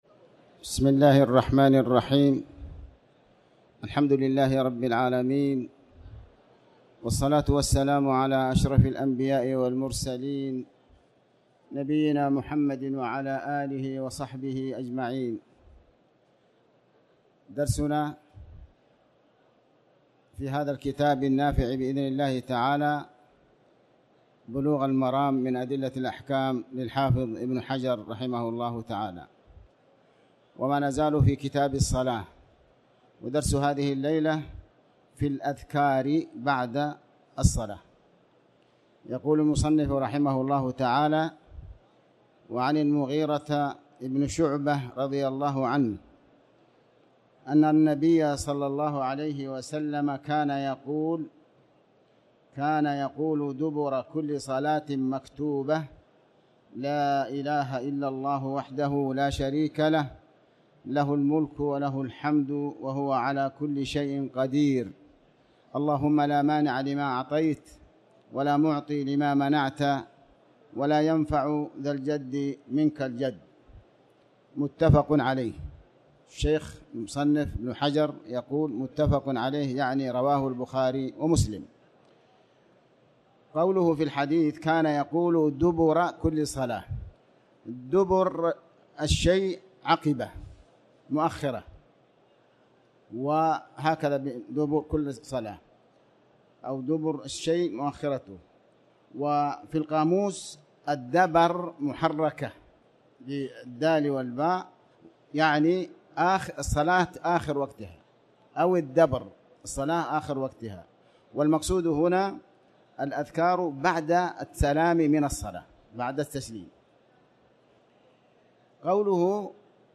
تاريخ النشر ٢١ ربيع الأول ١٤٤٠ هـ المكان: المسجد الحرام الشيخ